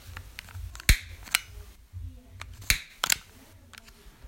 Ik maak het geluid 2x
aansteker! een lange